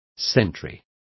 Complete with pronunciation of the translation of sentry.